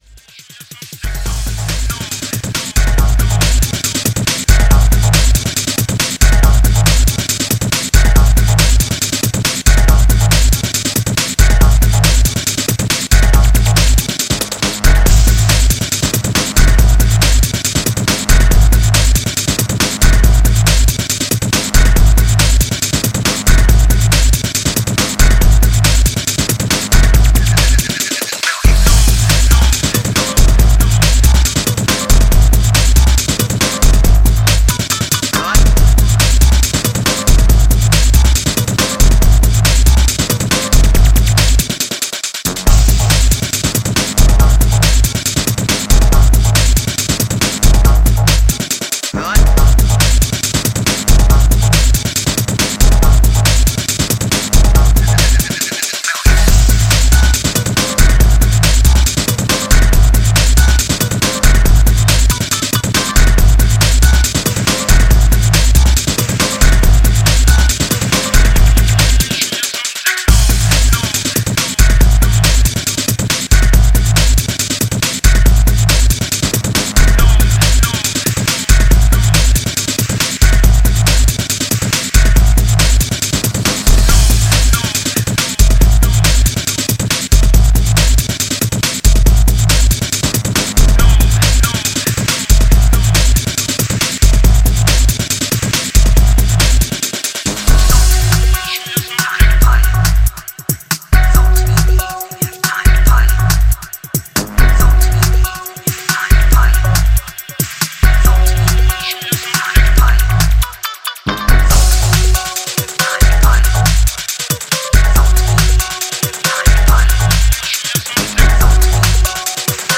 Drum & Bass
Urban Break-Beat, fusing old-skool jazz
sharp, precise and efficient